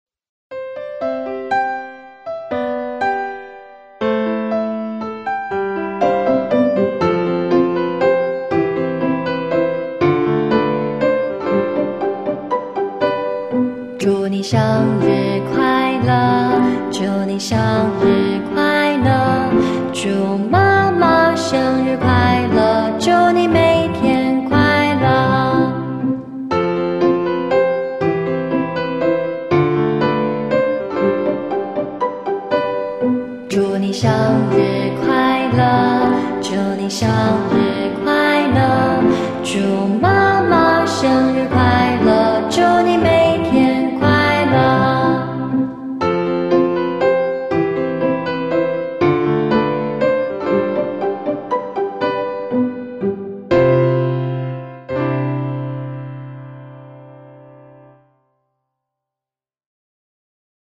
生日快乐歌 /shēngrì kuàilè gē/ Bài hát chúc mừng sinh nhật